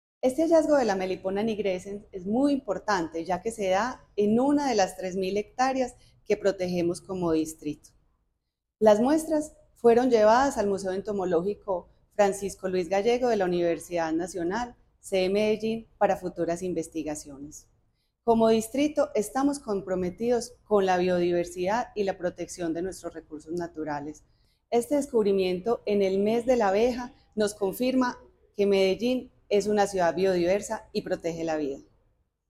Audio Declaraciones de la secretaria (e) de Medio Ambiente, Elizabeth Coral
Audio-Declaraciones-de-la-secretaria-e-de-Medio-Ambiente-Elizabeth-Coral-1.mp3